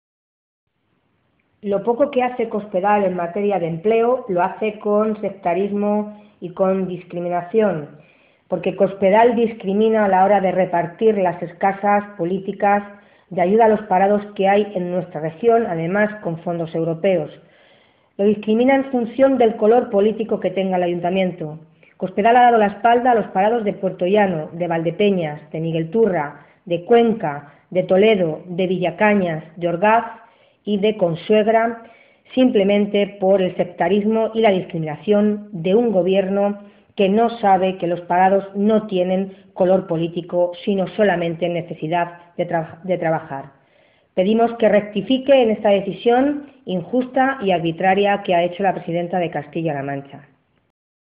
La portavoz de Empleo del PSOE en las Cortes regionales, Milagros Tolón, ha pedido hoy al Gobierno de Cospedal que “rectifique, escuche no solo a la oposición, sino a todos los agentes económicos y sociales y se ponga manos a la obra para abordar el principal problema que tenemos, que es el paro, a través de un gran pacto regional por el empleo”.
Cortes de audio de la rueda de prensa